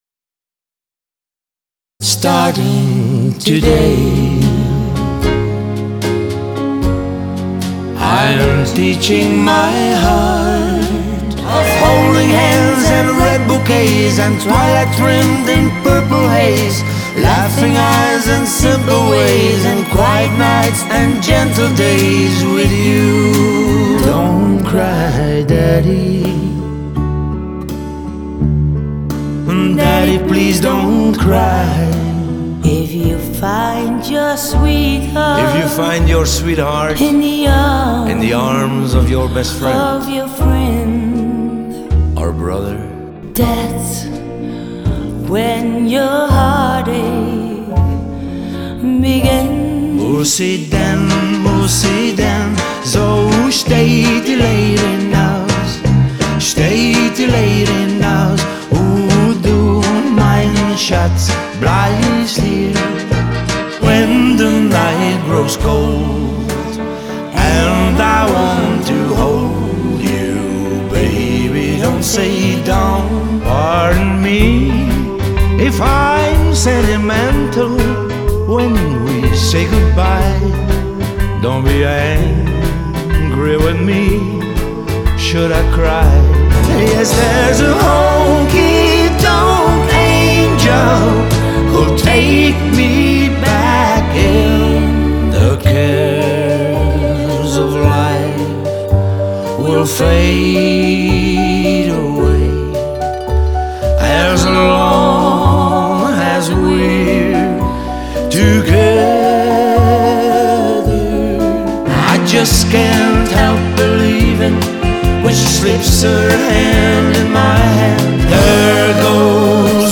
Duo Zanger (Gitaarbegeleiding)  en zangeres   Live on tape
medley